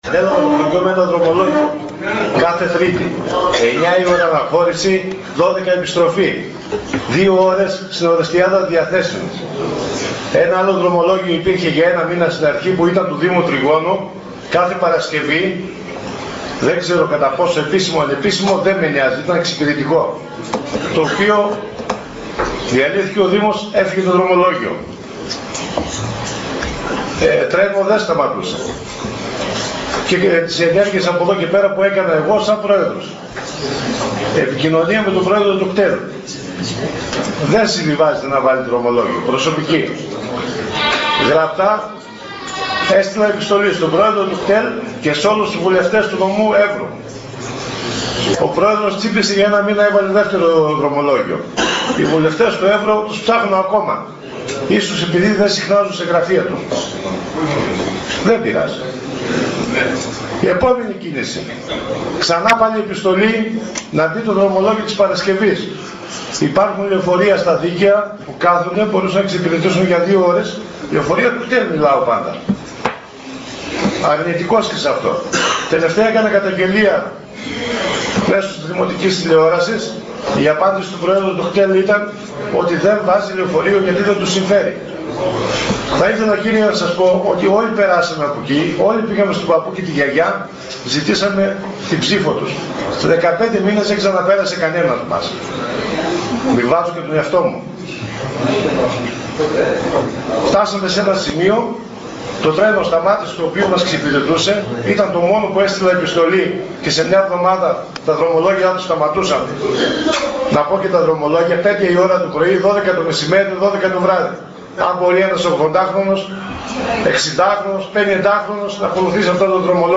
Πρόεδρος της Τοπικής Κοινότητας Μαρασίων κ.Χατζηκακίδης